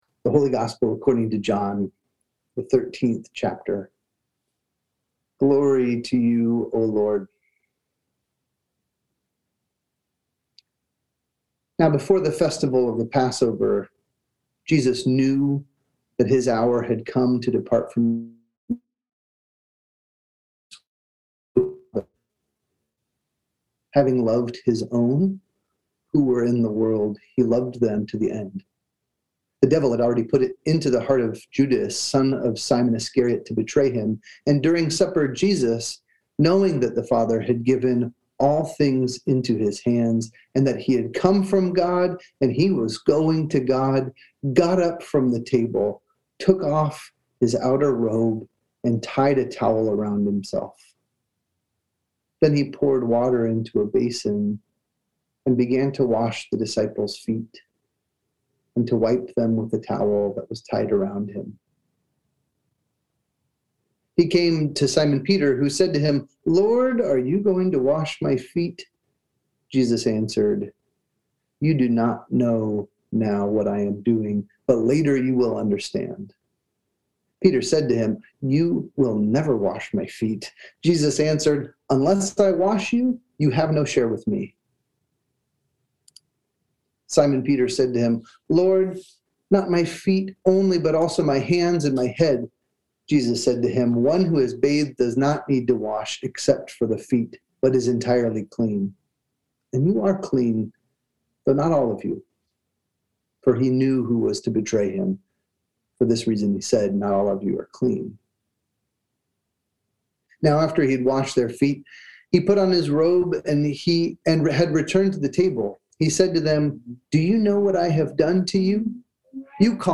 Sermons | Shepherd of the Valley Lutheran Church